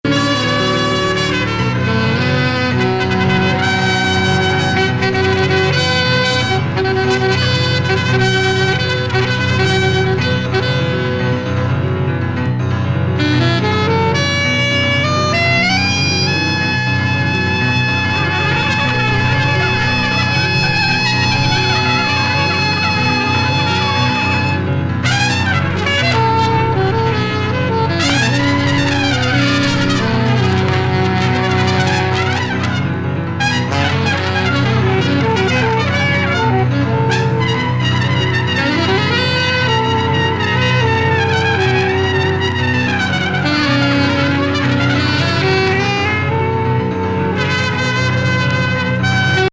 国内盤オンリー!デンマークでのライブ音源を収録。